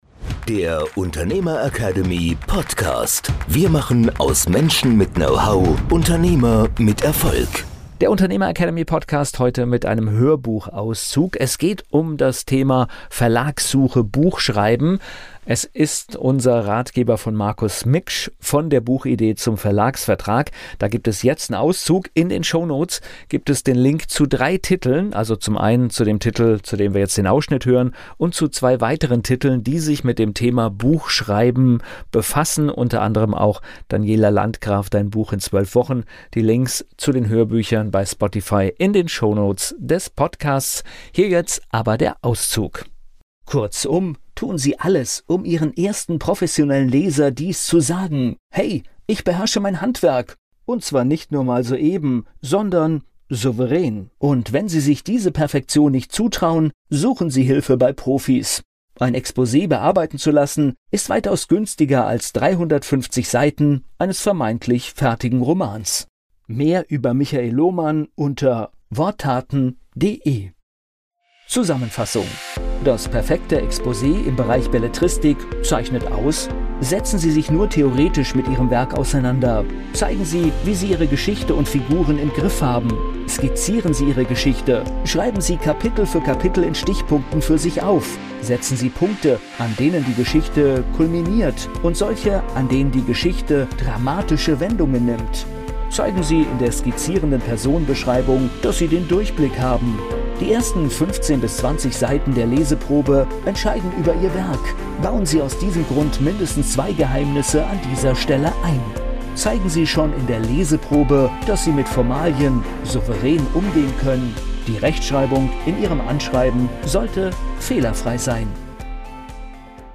In dieser Episode des UnternehmerAcademy Podcasts hörst du einen spannenden Hörbuchauszug